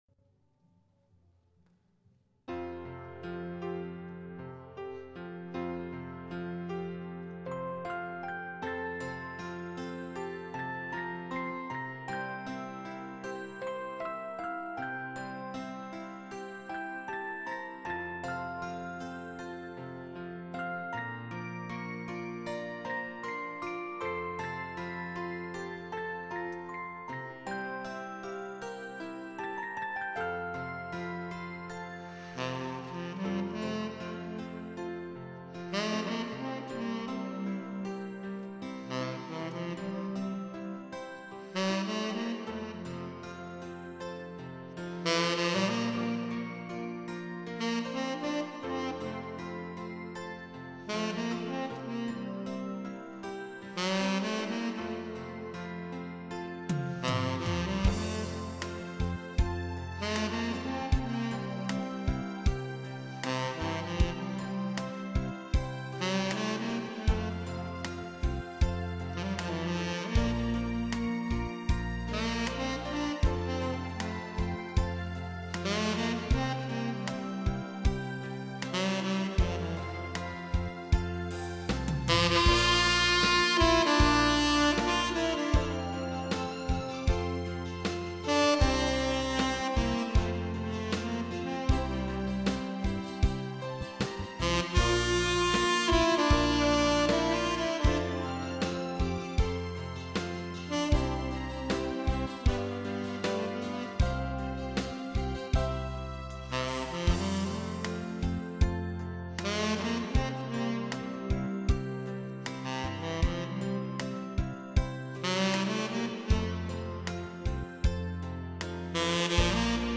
아마추어의 색소폰 연주